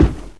/hl2/sound/npc/antlion_guard/far/
foot_heavy2.ogg